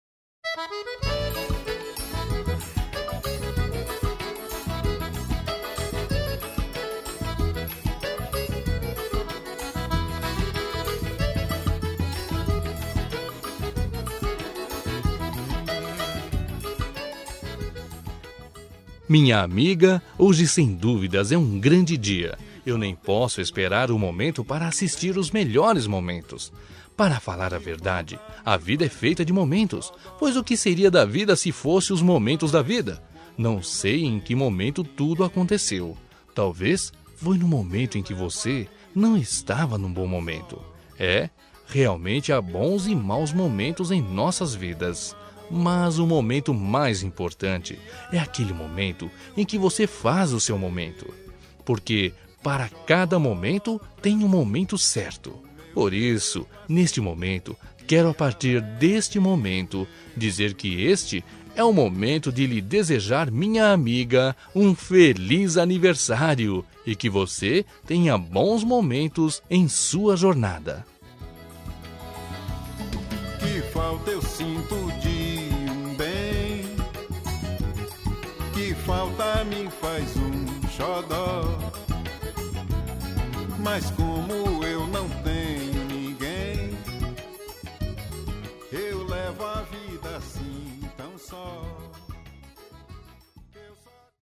Aniversário de Humor – Voz Masculina- Cód: 200203